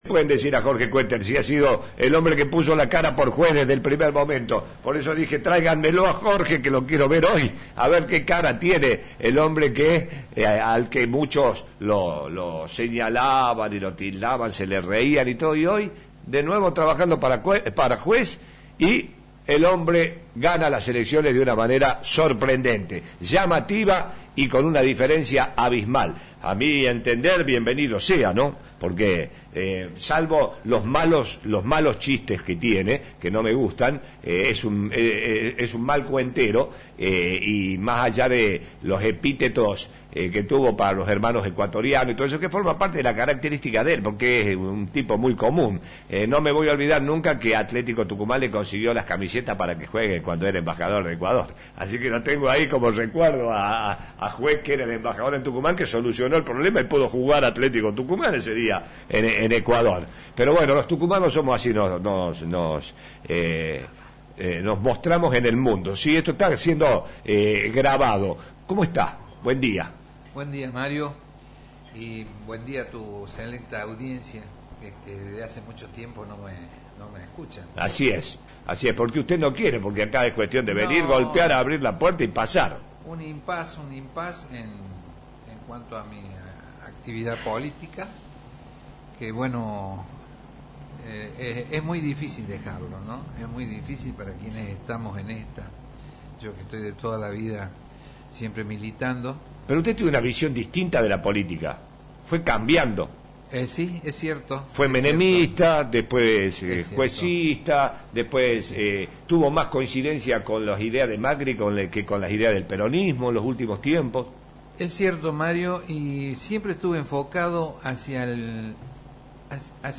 El dirigente visitó nuestros estudios e hizo un análisis de los resultados de las PASO y dijo que ahora viene un nuevo tiempo donde el kirchnerismo tenderá a desaparecer.